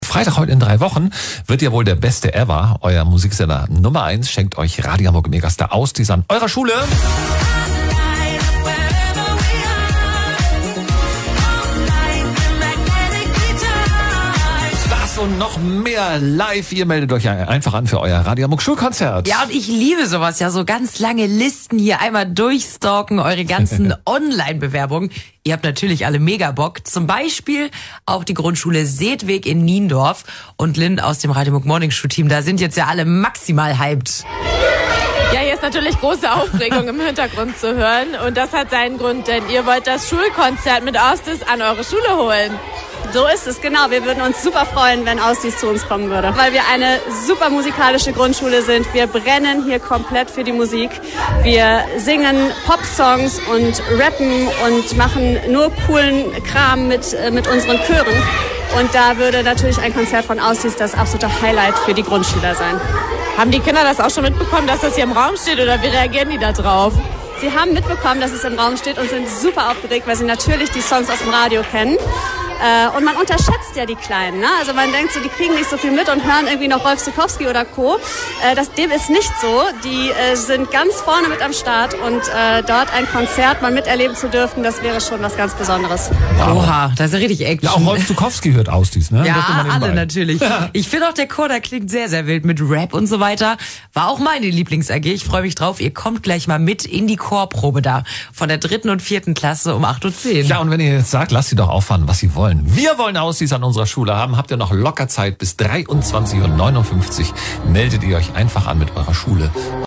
Radio Hamburg besuchte unsere Schule deshalb am 5. Februar und hat einen Mitschnitt vom Chor